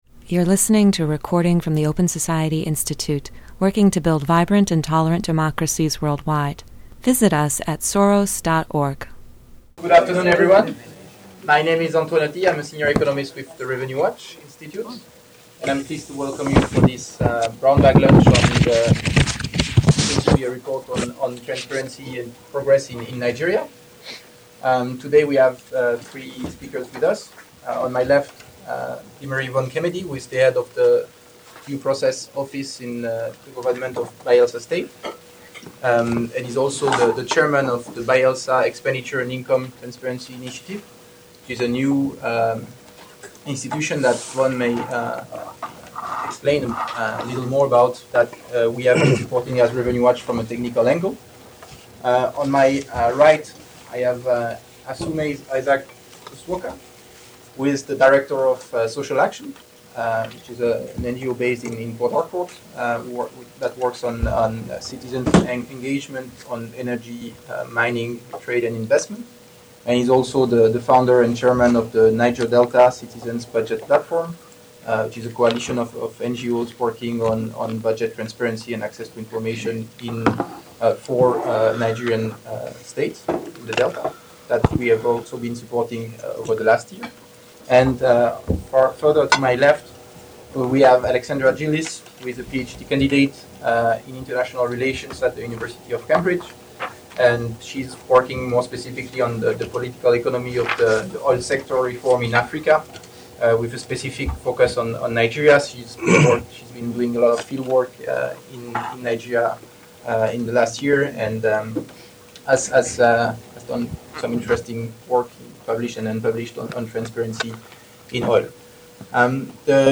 The Revenue Watch Institute, an Open Society Institute grantee, held a discussion with two leaders in Nigeria’s effort to reform management of natural resource revenues.